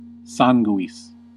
Ääntäminen
Ääntäminen France: IPA: /sɑ̃/ Haettu sana löytyi näillä lähdekielillä: ranska Käännös Ääninäyte 1. sanguis {m} 2. cruor {m} Suku: m .